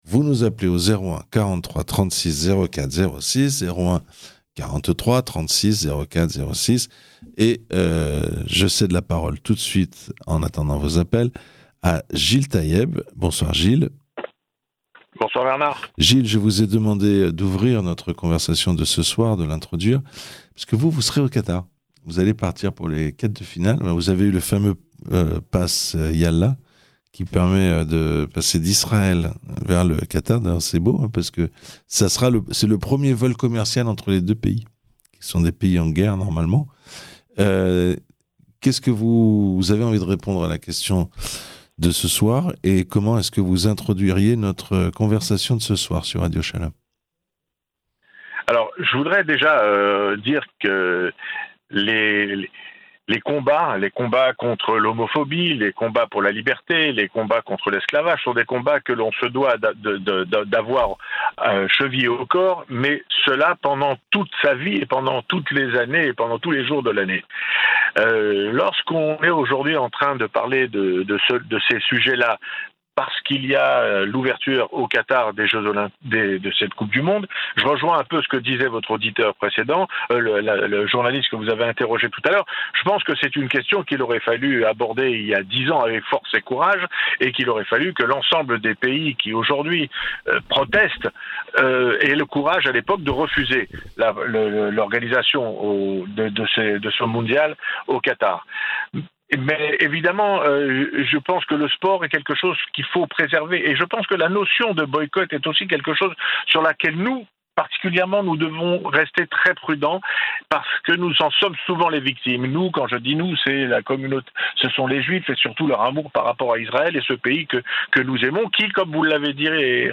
Question : faut-il se plier aux règles du oays qui accueille le Mondiale ou chacun doit-il venir tel qu'il est, avec ses valeurs ? Les auditeurs ont la parole